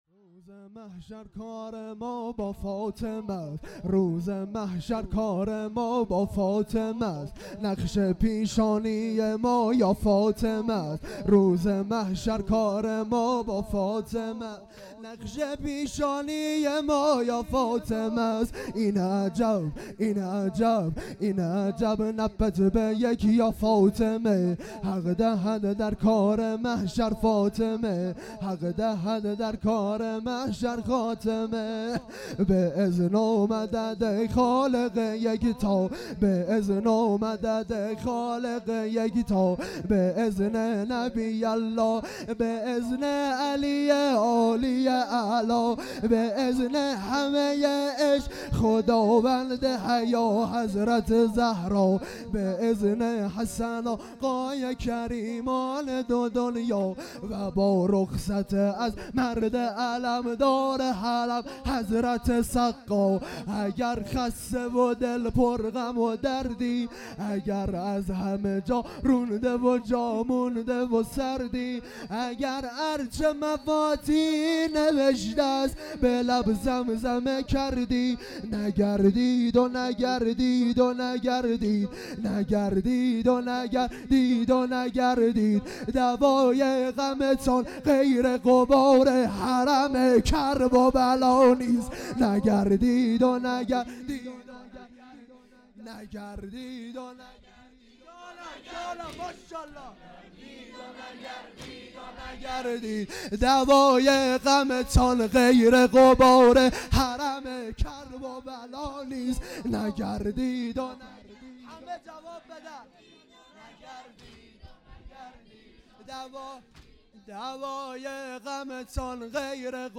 دبیرستان امام سجاد علیه السلام | شب دوم
یادمان فاطمیه